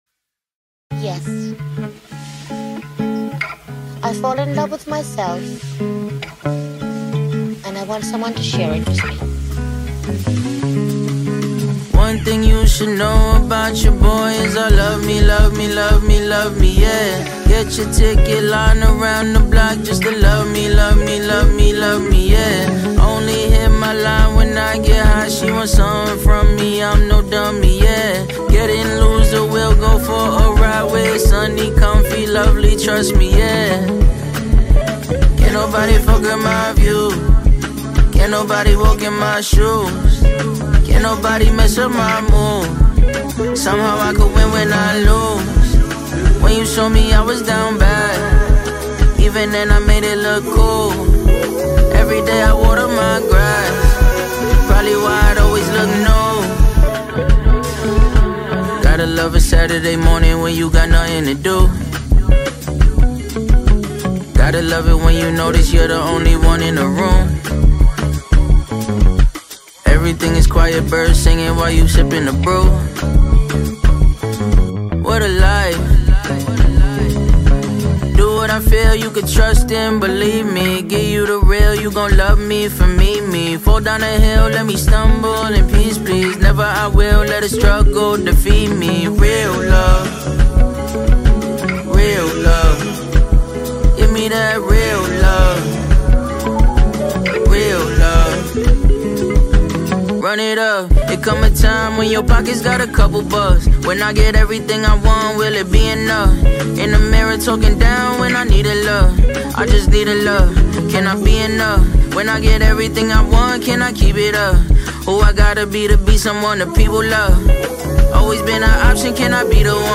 heartwarming song